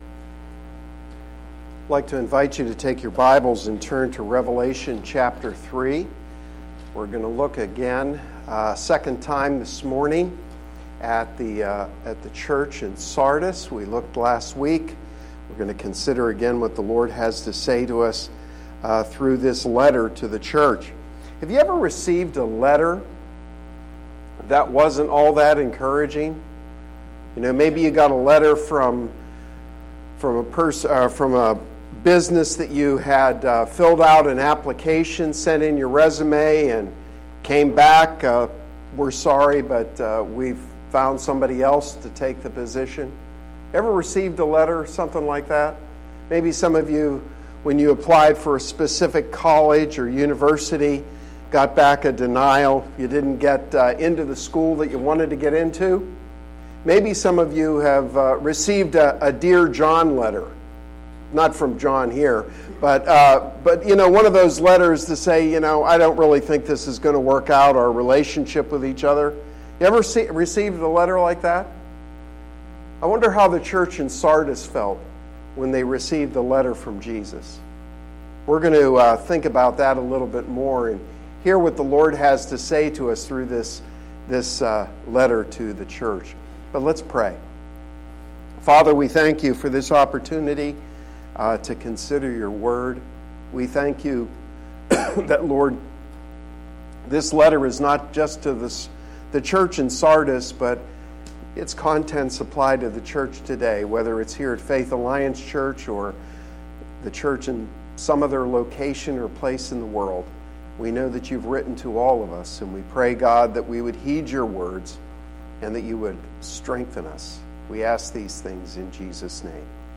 Sermon-5-5-19.mp3